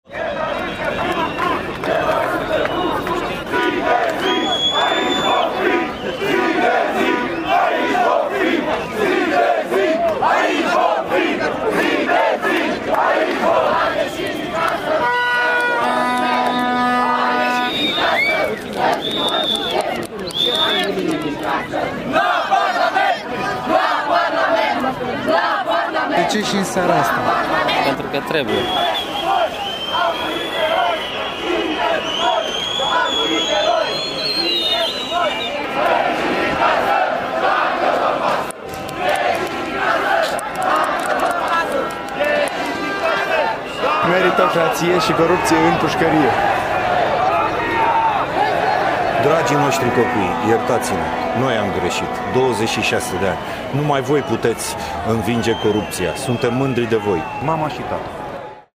“Peste tot corupție, nicăieri justiție!”, “Toate partidele fură prin rotație!”, “Toate partidele, aceeași mizerie”, “Vrem spitale, nu catedrale!”, “Daniel, Daniel, o să te deranjăm nițel!”, “Vrem 300, nu 600!”, “Colectiv!”, “România, trezește-te!”, “Corupția ucide!”, “Jos sistemul mafiot!” și “Anticipate!”, au fost câteva din scandări.
05nov-07-Material-vox-si-ambianta-protest.mp3